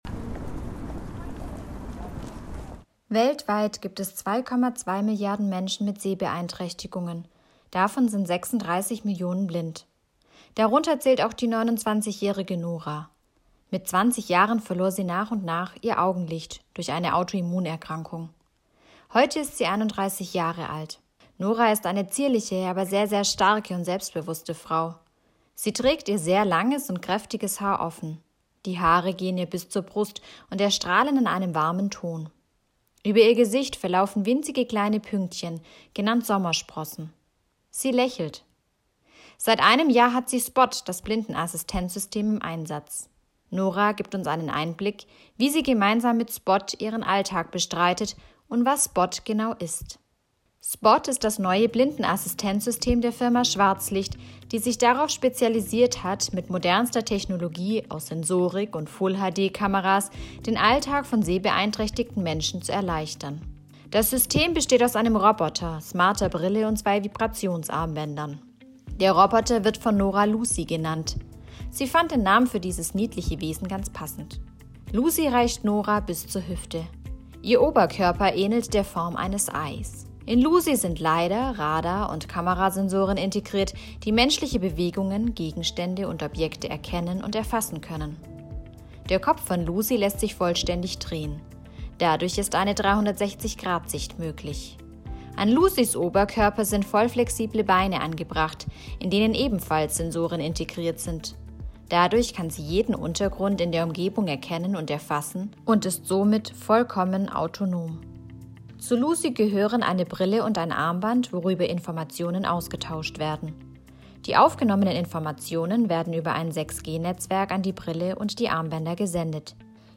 Audiodeskription zum Blindenassistenzsystem